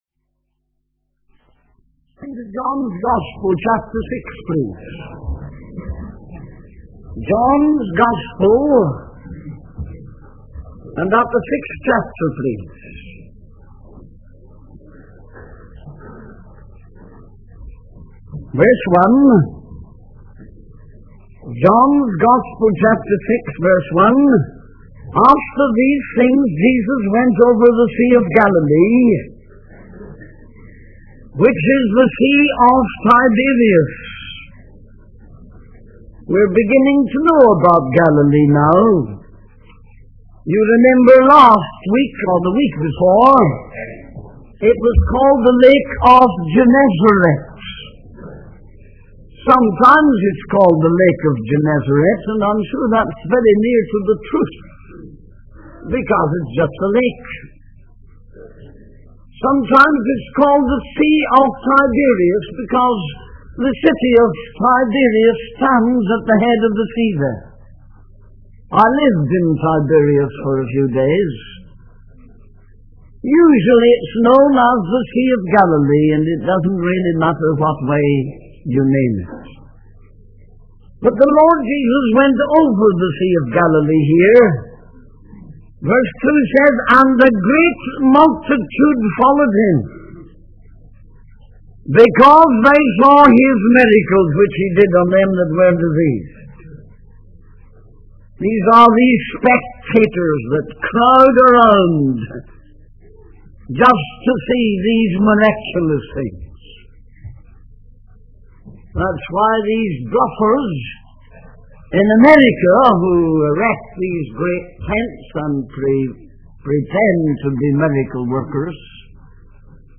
In this sermon, the preacher begins by singing a hymn and offering a prayer. He then discusses the topic of false preachers who claim to perform miracles but do not truly love Jesus. The preacher then transitions to discussing the sermon on the Mount in the book of Matthew.